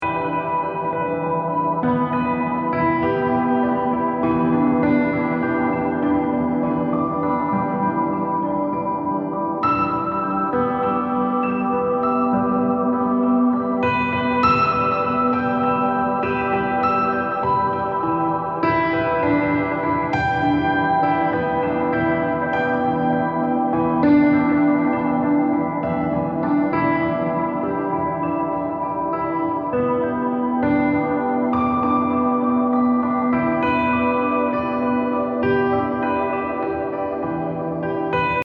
BPM60-69 calm Healing Instrument Soundtrack 癒し 穏やか
BPM 50